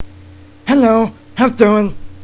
So in my vast amount free time, i have recorded myself saying a number of things retardely.